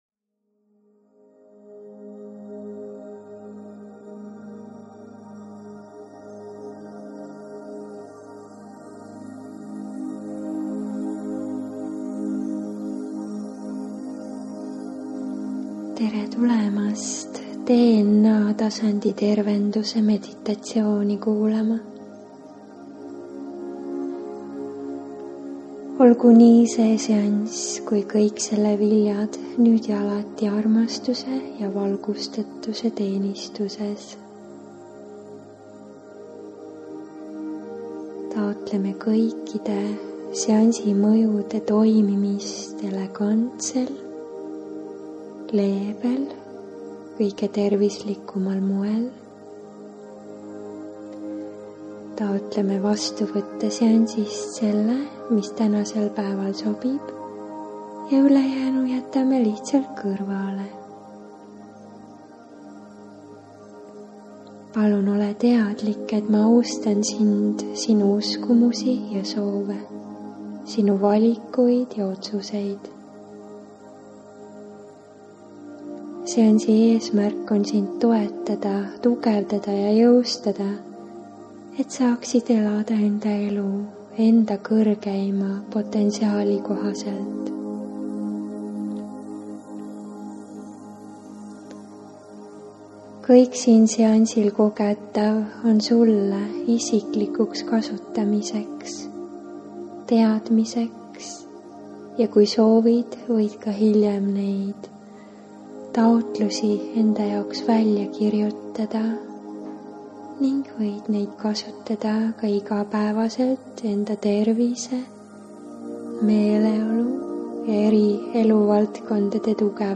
SIIT LEHELT SAAD KUULATA MEDITATSIOONI DNA HÄÄLESTAMINE TERVISELE JA TERVIKLIKKUSELE Kas oled teadlik, et su DNA reageerib väliskeskkonnas toimuvale - sellele, mis keskkonnas viibid, mida kuulad, vaatad, sööd ja mõtled?!